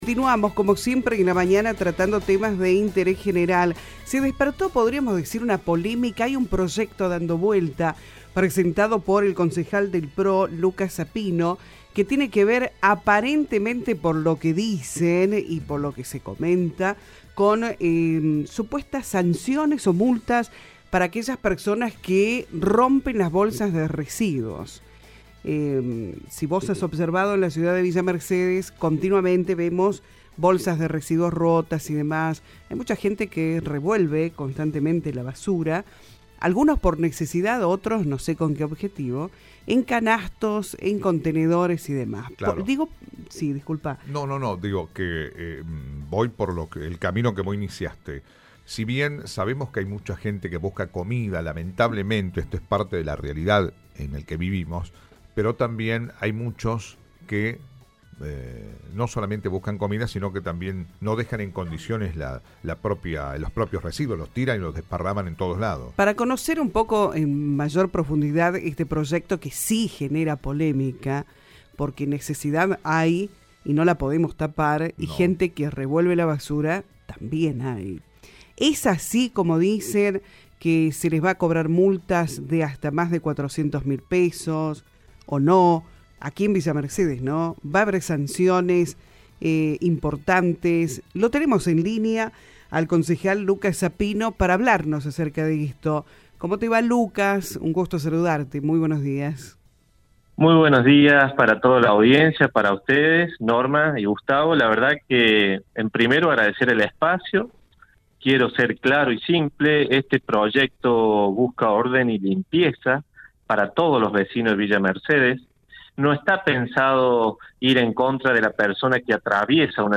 Los puntos clave de la entrevista: El […]
En una entrevista exclusiva con “Viva la Mañana”, el concejal del PRO, Lucas Sapino, salió a aclarar los detalles de un proyecto de ordenanza que ha generado intensos debates y versiones encontradas en los últimos días, especialmente sobre las supuestas multas que podrían llegar hasta los $400.000 pesos.